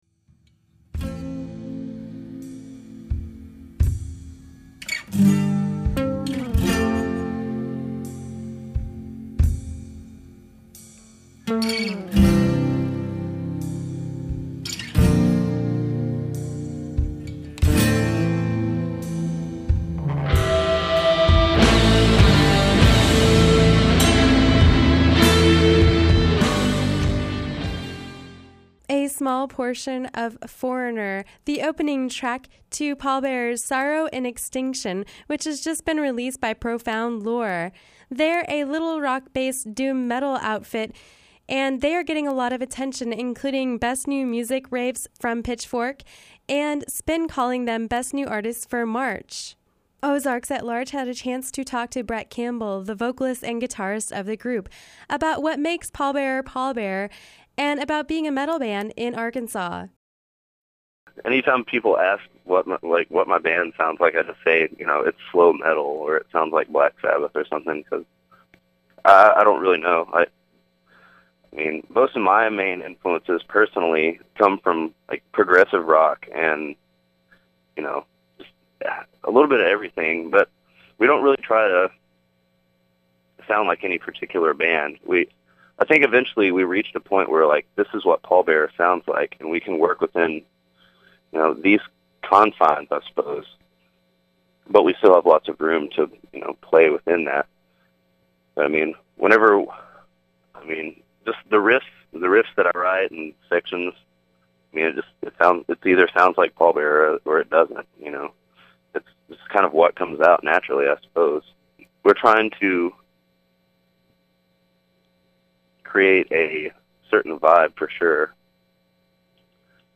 A Conversation with “Pallbearer”